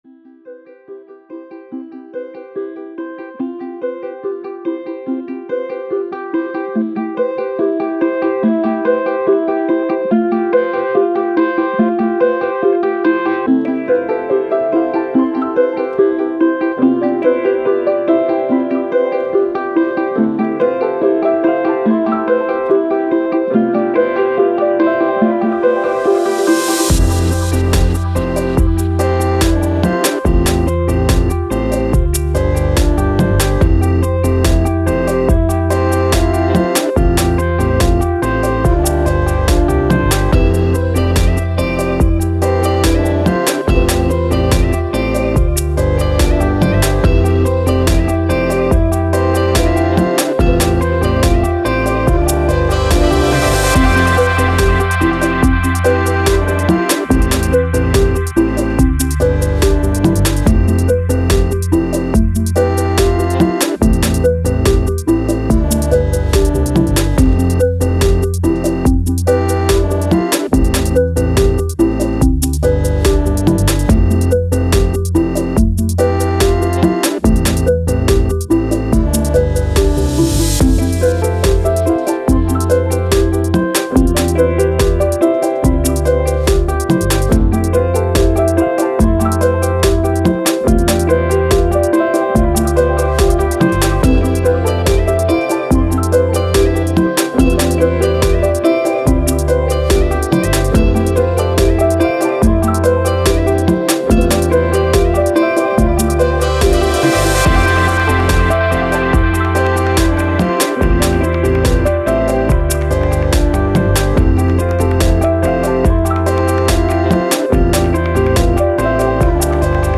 Pop, Uplifting, Glitch, Positive